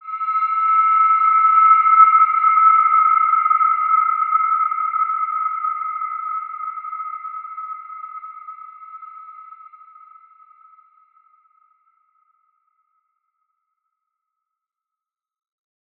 Wide-Dimension-E5-mf.wav